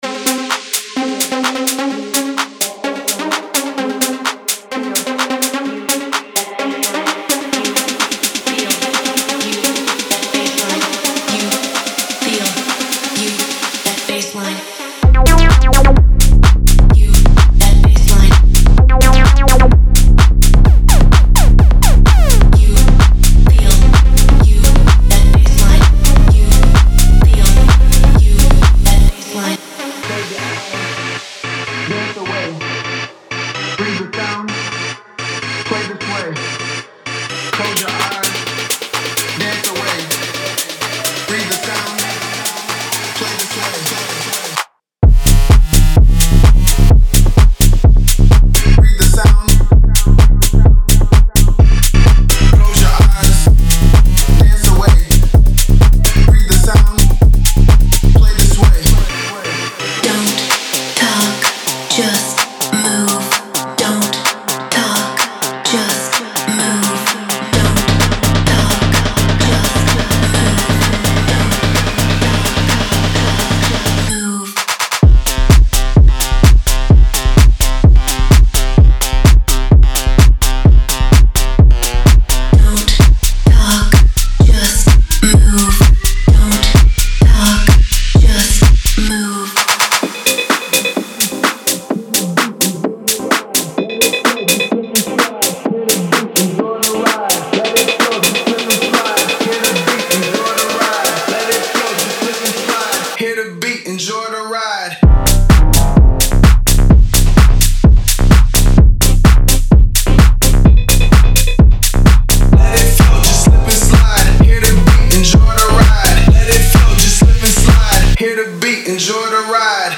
デモサウンドはコチラ↓
Genre:Tech House
Tempo/Bpm: 128